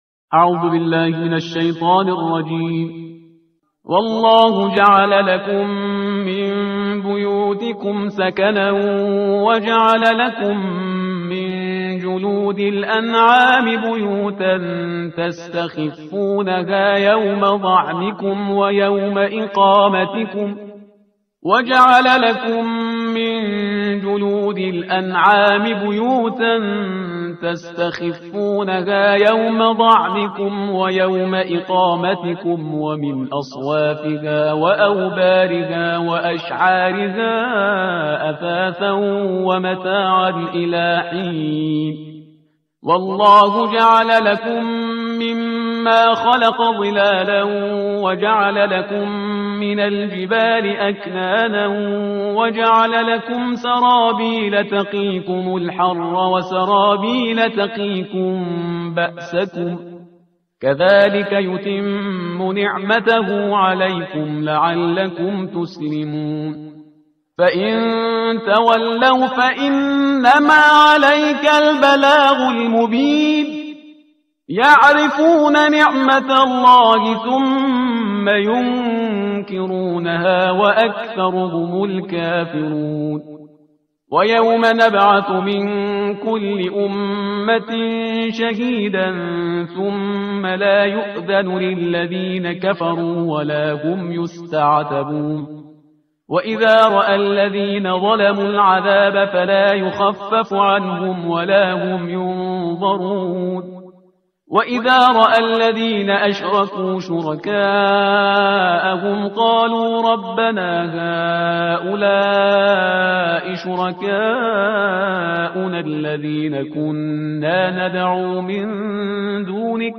ترتیل صفحه 276 قرآن با صدای شهریار پرهیزگار